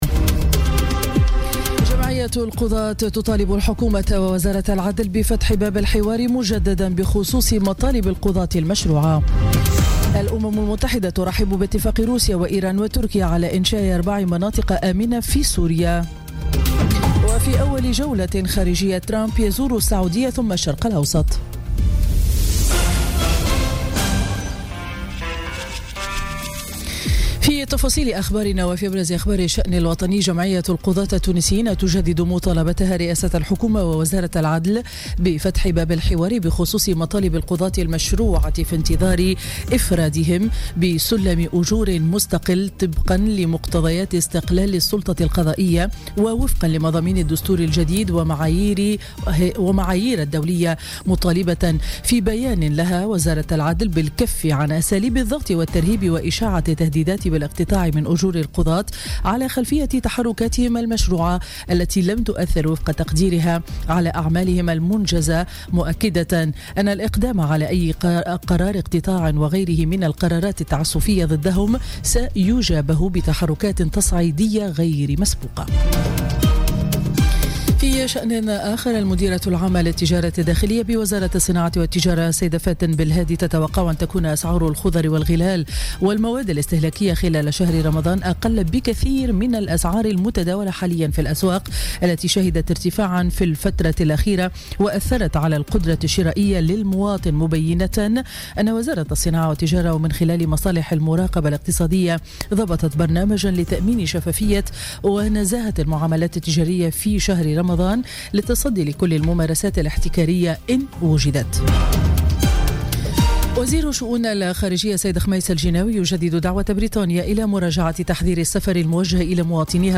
نشرة أخبار السابعة صباحا ليوم الجمعة 5 ماي 2017